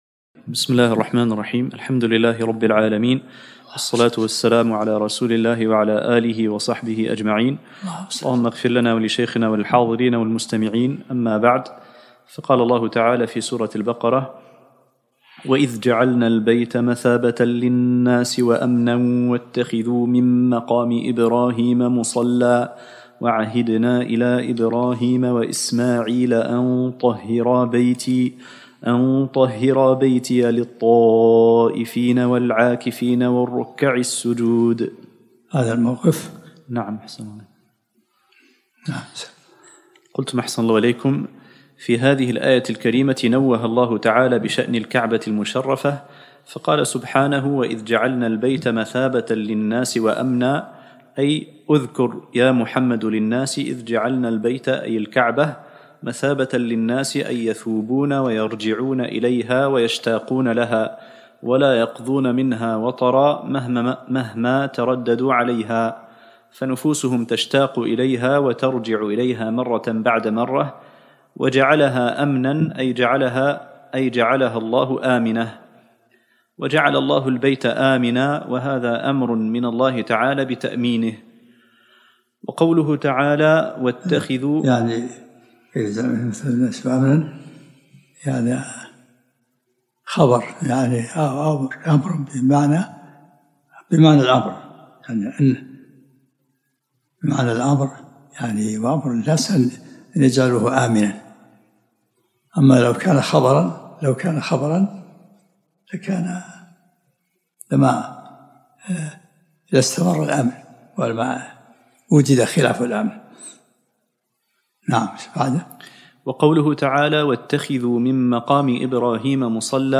الدرس التاسع من سورة البقرة